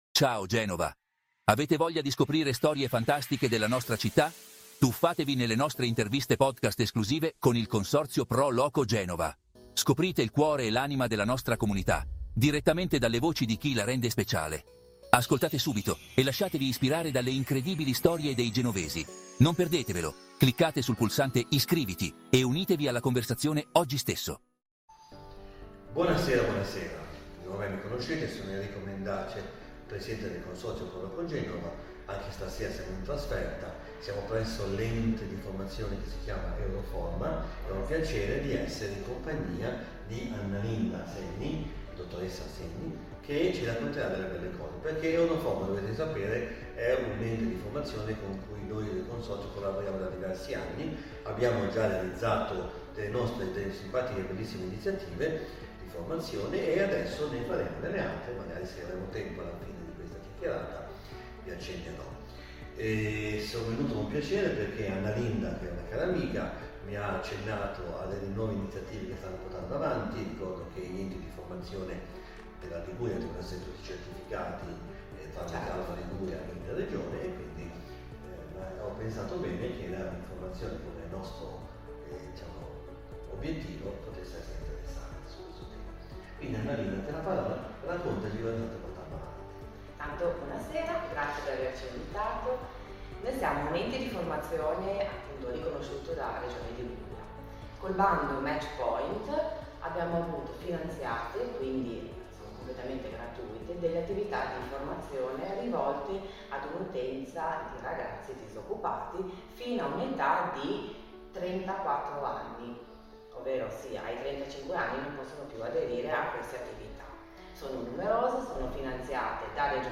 Consorzio Pro Loco Genova Intervista